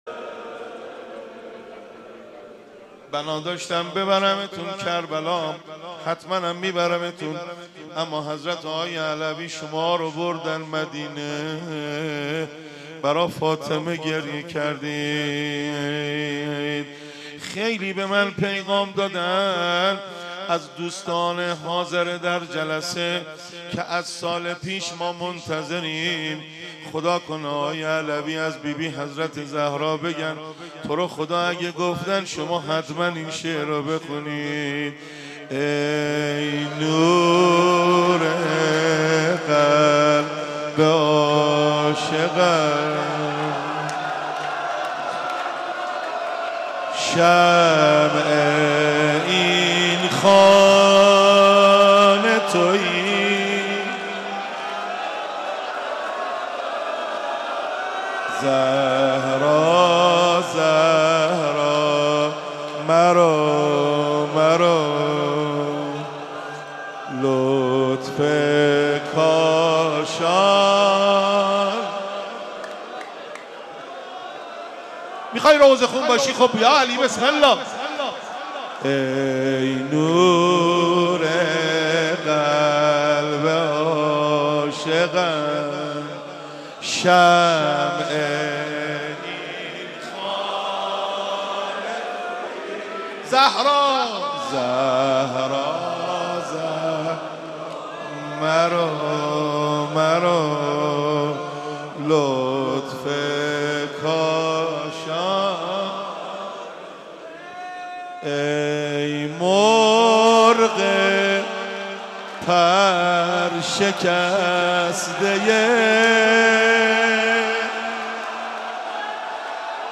شب بیست و سوم ماه رمضان
روضه